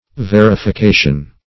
Verification \Ver`i*fi*ca"tion\, n. [Cf. F. v['e]rification.]